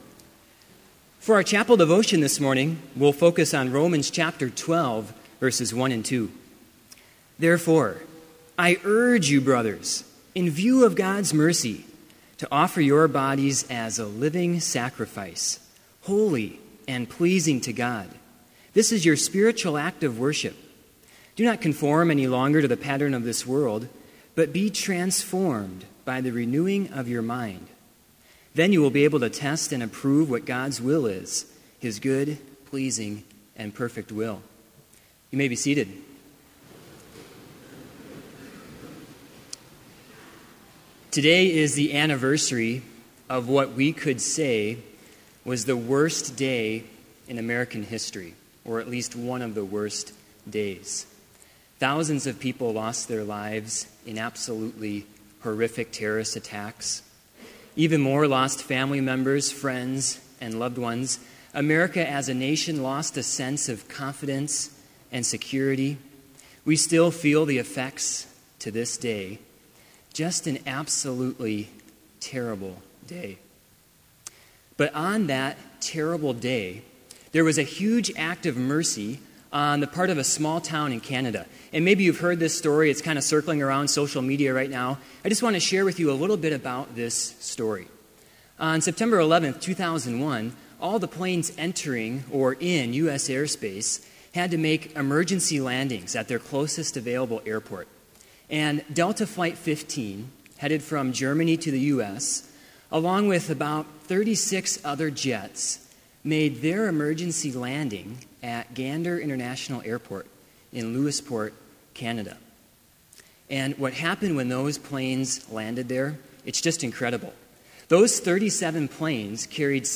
Complete service audio for Chapel - September 11, 2015